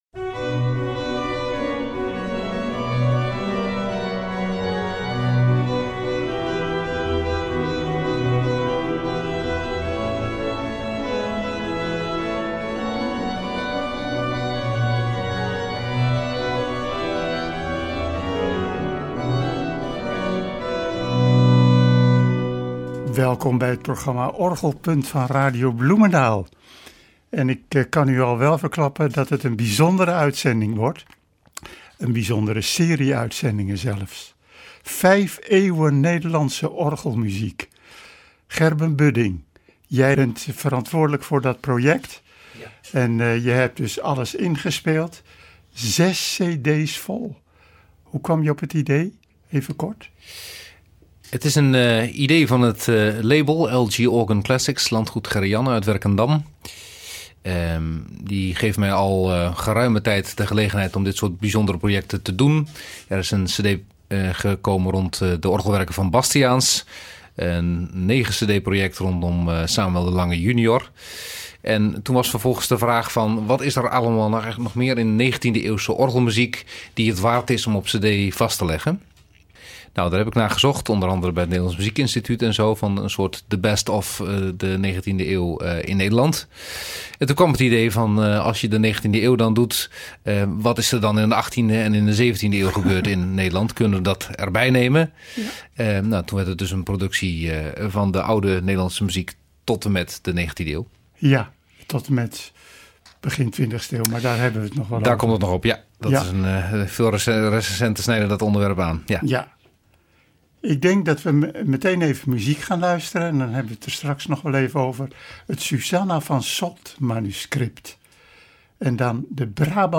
Niet minder dan 6 cd’s dus, vol met verrassende composities gespeeld op verschillende orgels, die passen bij de verschillende tijdsperioden.
Daarna klinken twee psalmbewerkingen uit het Lynar B7 manuscript.